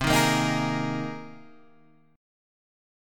Cm7#5 chord {8 6 8 8 9 6} chord